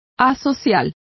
Complete with pronunciation of the translation of asocial.